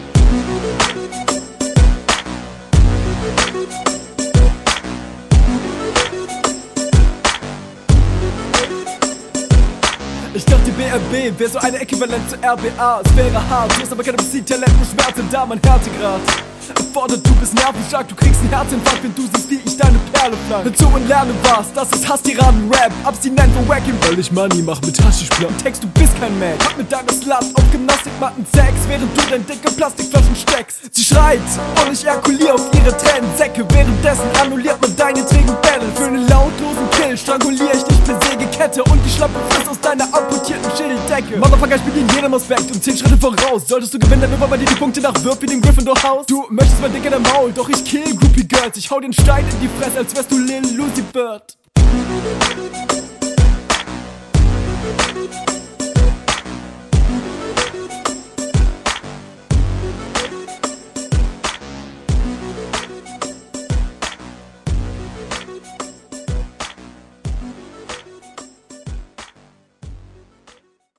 gute ansätze, aber zu oft klar aus dem takt rausgerutscht
Klingt geil geflowt aber klingt eher nach nem Song wo man mal die ein oder …